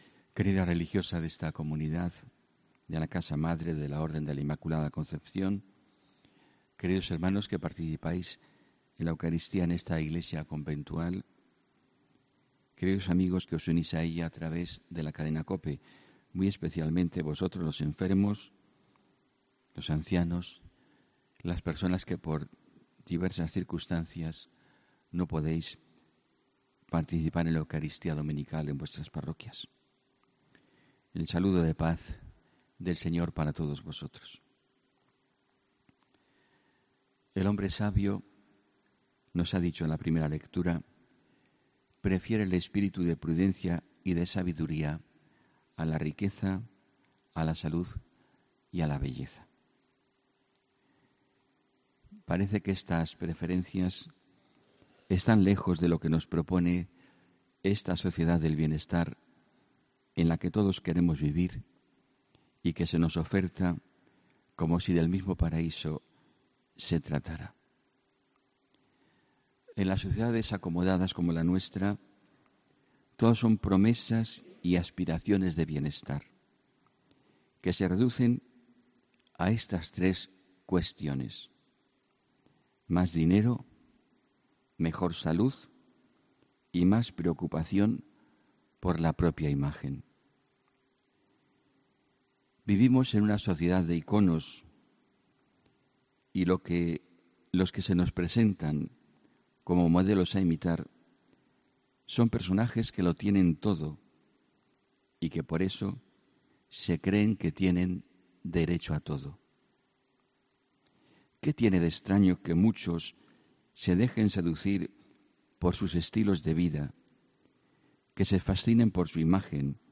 HOMILÍA 14 OCTUBRE